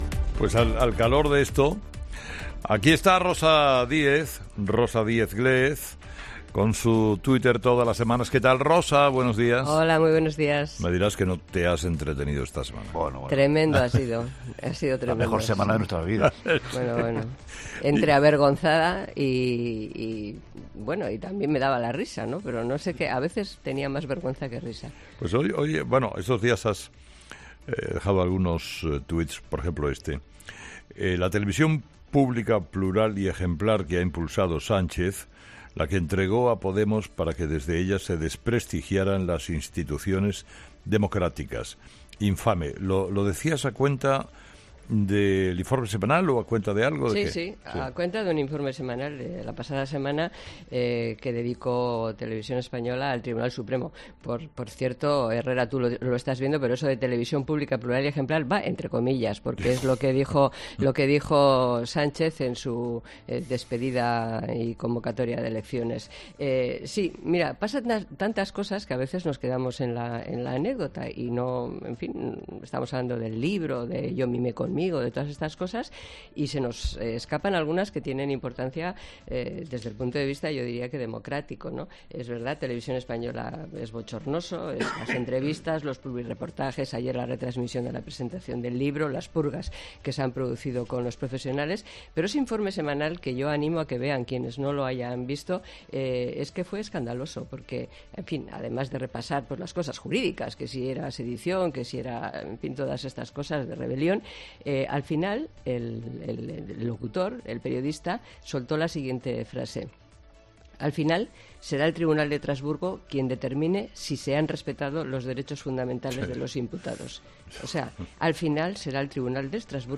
1. Herrera se parte de risa con la chirigota de Cádiz sobre la casa de Iglesias en Galapagar
La chirigota sevillana 'Los dependentistas' hicieron las delicias del Teatro Falla en el COAC (Concurso Oficial de Agrupaciones Carnavalescas de Cádiz).